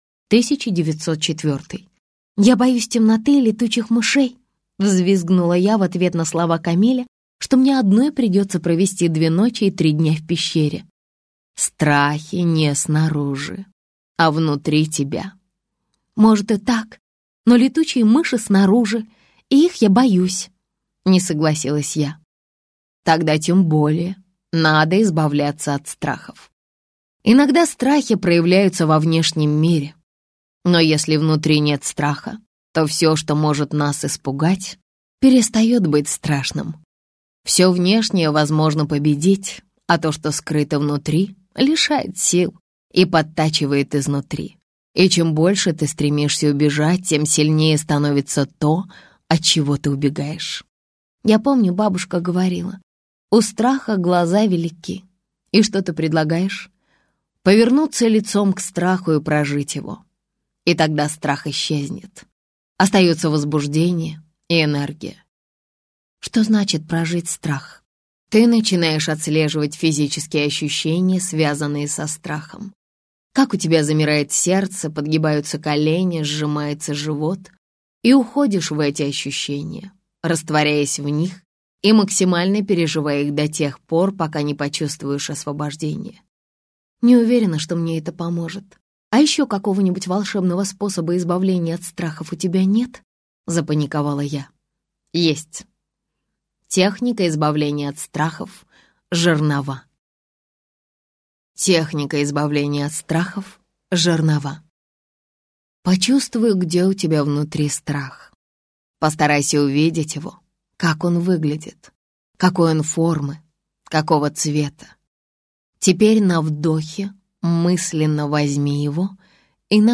Аудиокнига Открывая новую себя. Твой путь к счастью, могуществу и любви | Библиотека аудиокниг